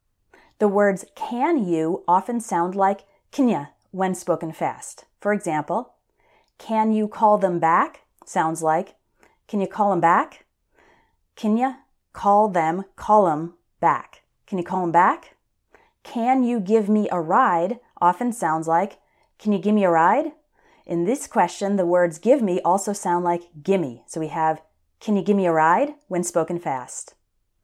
One reason for the difficulty is that when native English speakers are talking fast, we often change how we pronounce the words at the beginning of questions.
Can You >> Kinya